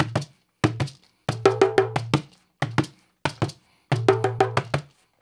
92-PERC2.wav